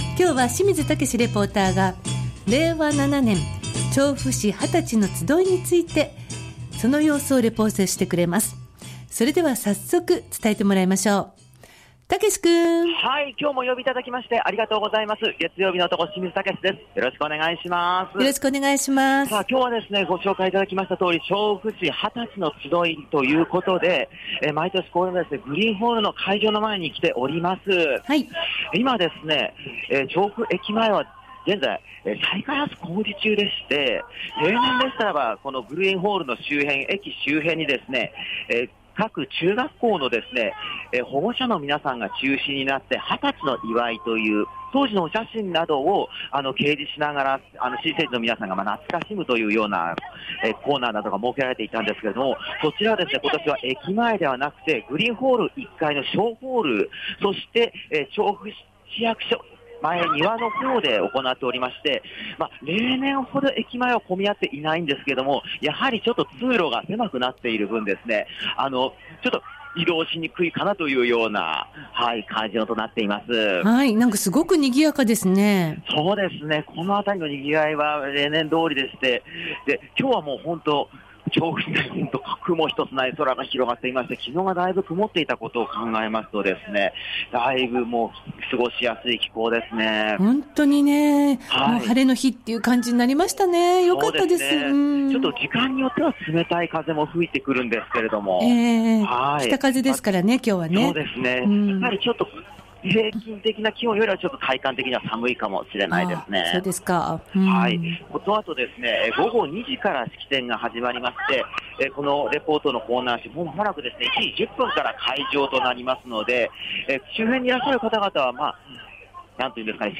調布市成人式の開式前の様子を、 調布駅前広場からお伝えしました！！
成人の日、今年も雲ひとつない青空の下からお届けした本日の街角レポートは旧・成人式こと「二十歳のつどい」のレポートです。
そんな世代の皆さん10人ほどにお話を伺いました。